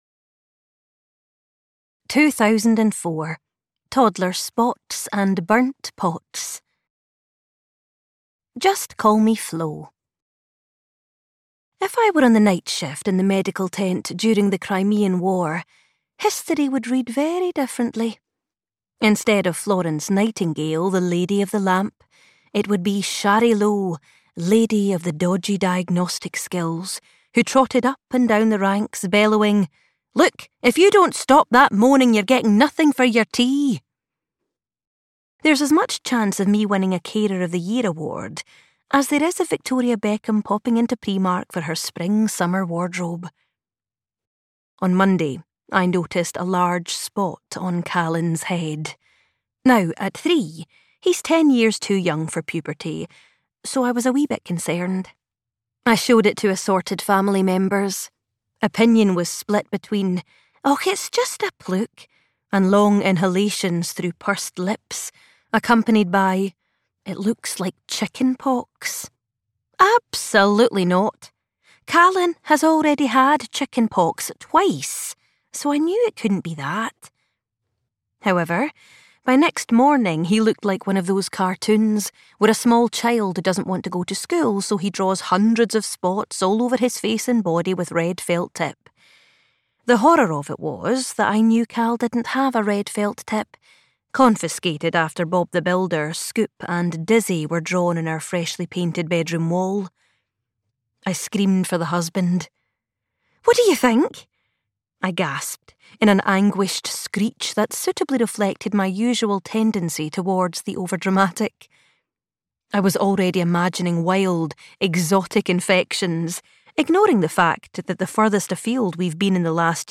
Because Mummy Said So (EN) audiokniha
Ukázka z knihy